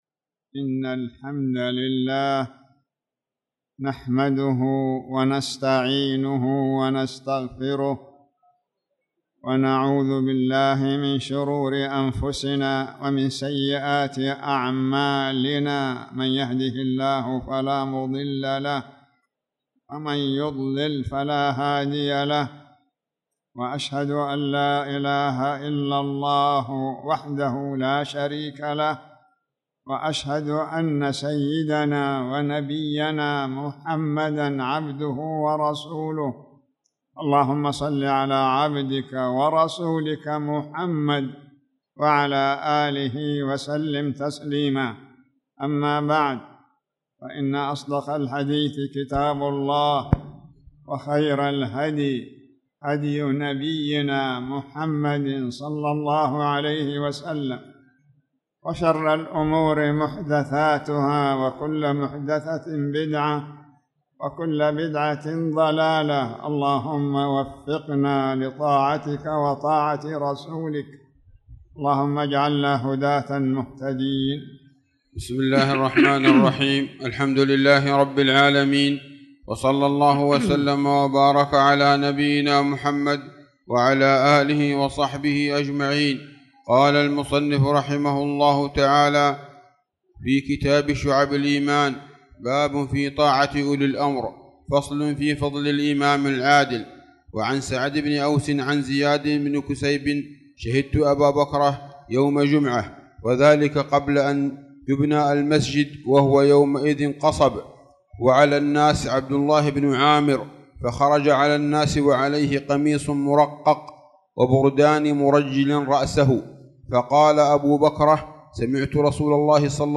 تاريخ النشر ١٠ شعبان ١٤٣٧ هـ المكان: المسجد الحرام الشيخ